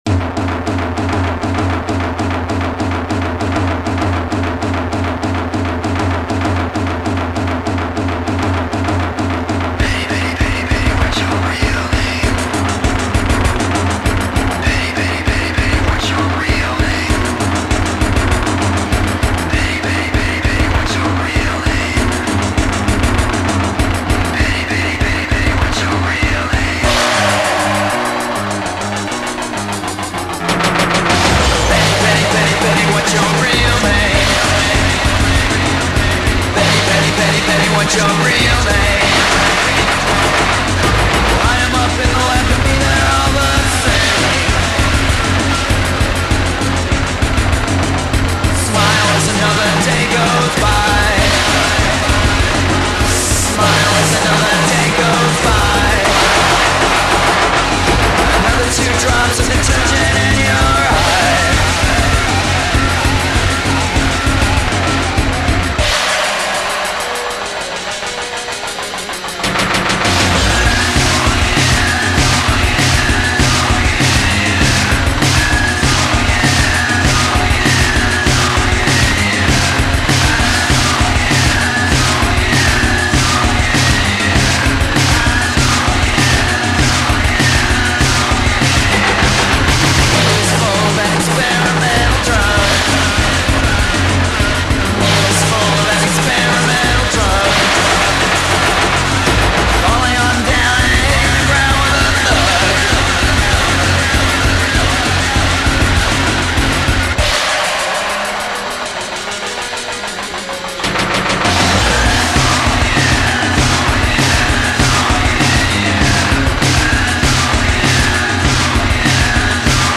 industrial-sounding material
guitars, basses, keyboards, programming, vocals